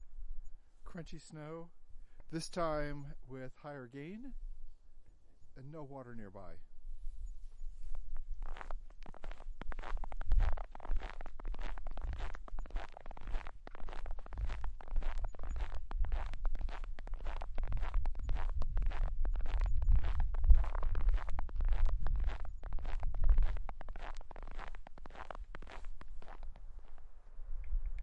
努纳维克 " 雪地冰屋块吱吱作响的坚硬的冷哇处理
描述：雪冰屋块吱吱作响的硬冷哇处理
Tag: 冰屋 吱吱响 处理